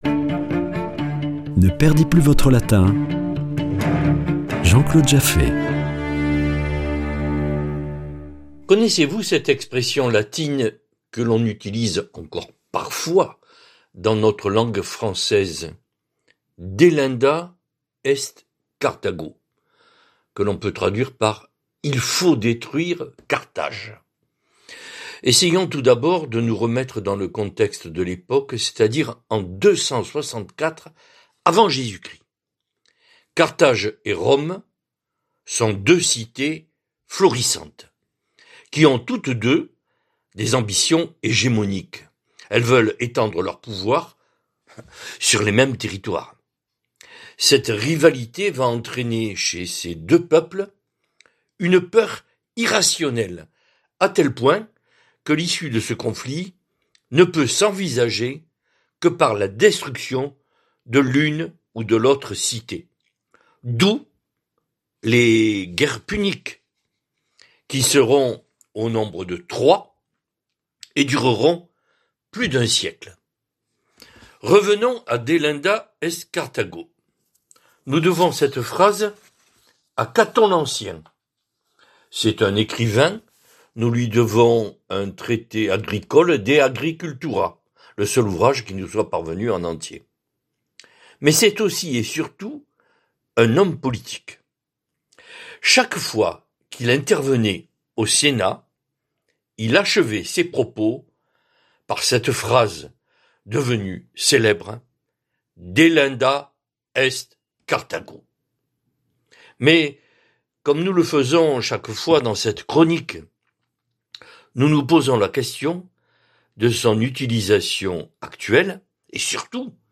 Chronique Latin